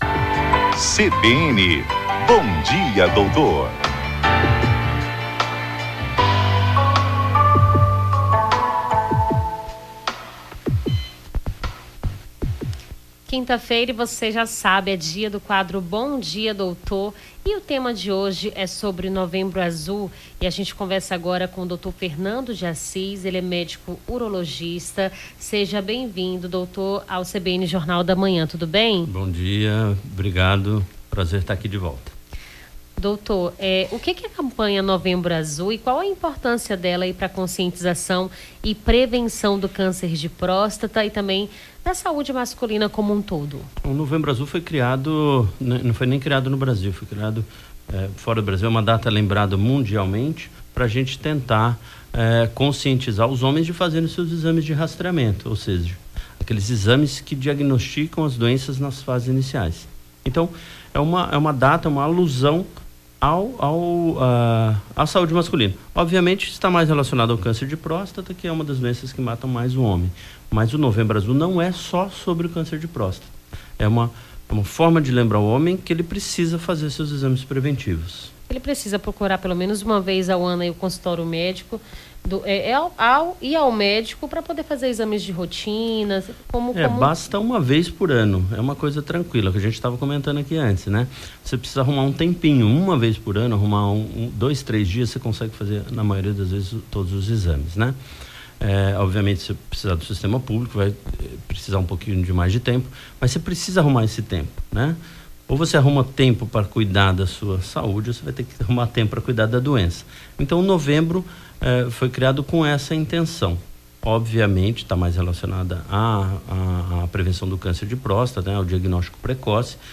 Novembro Azul Câncer de Próstata urologia